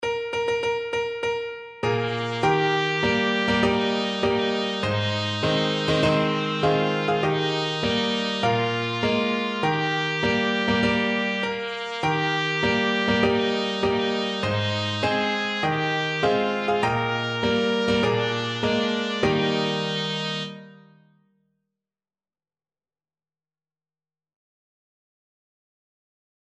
Trumpet
With gusto!
4/4 (View more 4/4 Music)
Bb4-Bb5
Eb major (Sounding Pitch) F major (Trumpet in Bb) (View more Eb major Music for Trumpet )
Traditional (View more Traditional Trumpet Music)
Bavarian Music for Trumpet
ein_prosit_TPT.mp3